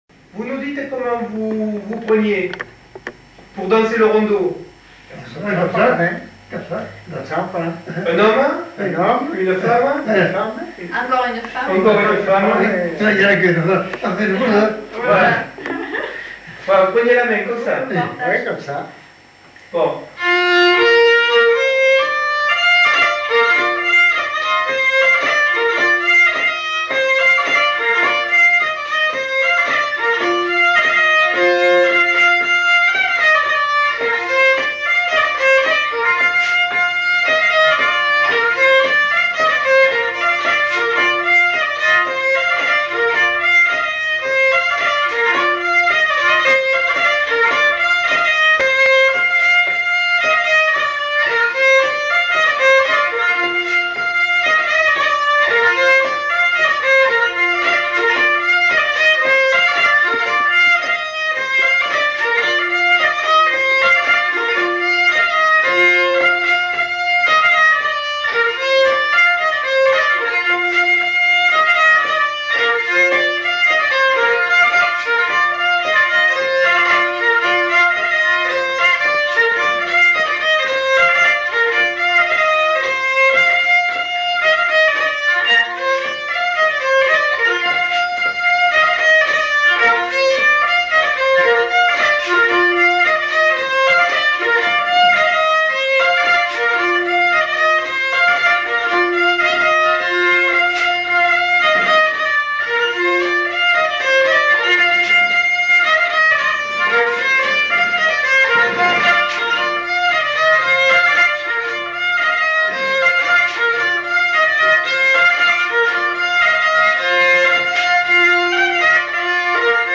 Aire culturelle : Petites-Landes
Genre : morceau instrumental
Instrument de musique : violon
Danse : rondeau